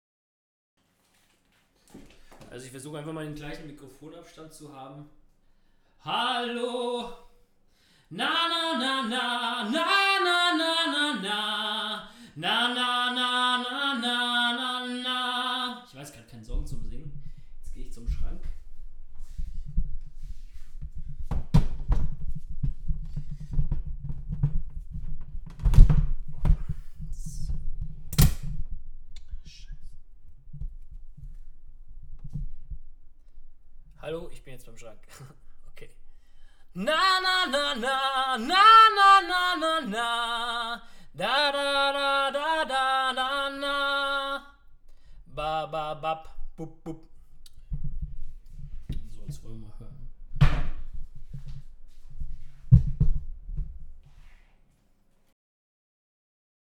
Einmal war ich ganz normal im Raum und bin dann zum Schrank hin. Ich kann zwar leider nicht wirklich da rein, aber das scheint schönmal nan Unterschied zu machen. Anhänge Test-Raum.mp3 Test-Raum.mp3 2,2 MB · Aufrufe: 202